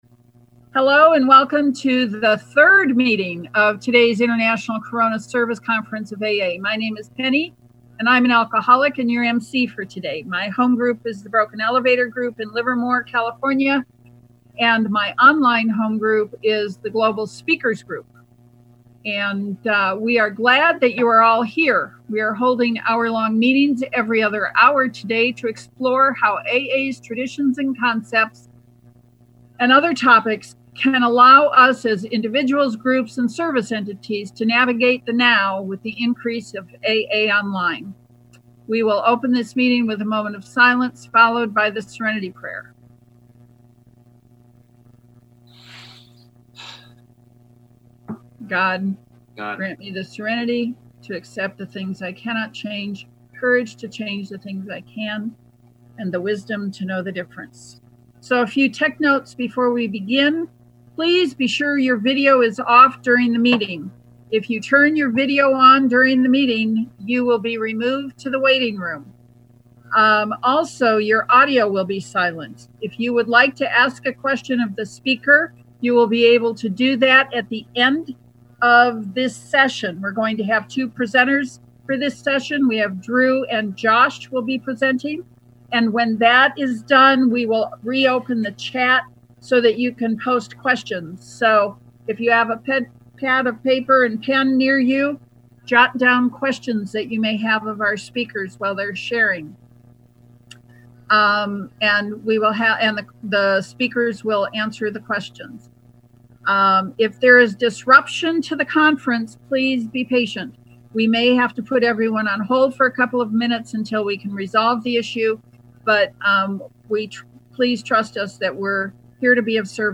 Technology International Corona Service Conference of AA Worldwide 4-4-2020